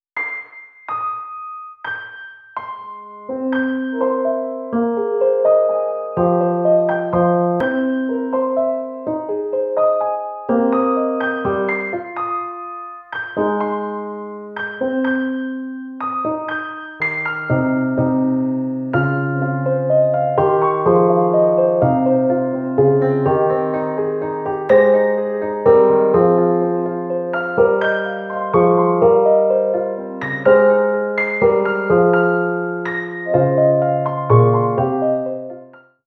Exemples de sonorités du CRDL
Impressioniste
9_IMPRESSIONIST.wav